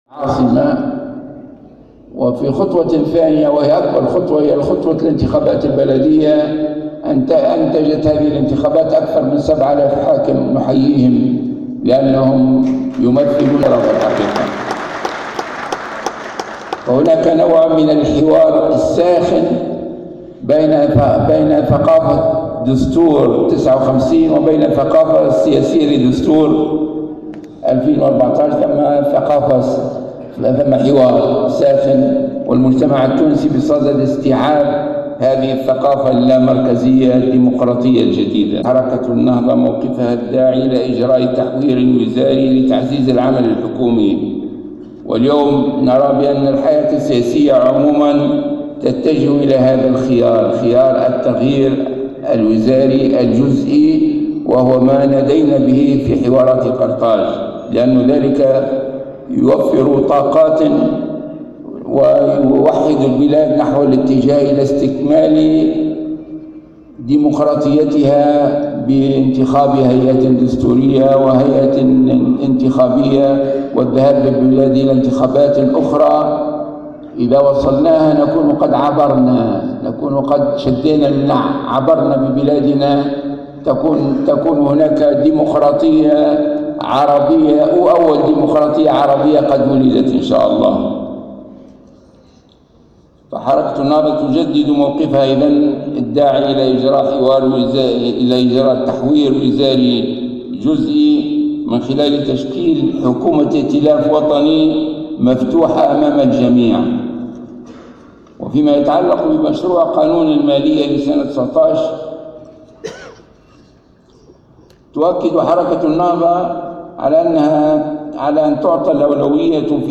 ودعا راشد الغنوشي في كلمته التي ألقاها، اليوم السبت، خلال الندوة السنوية الثانية لإطارات حزب حركة النهضة، إلى تشكيل حكومة ائتلاف وطني "مفتوحة أمام الجميع".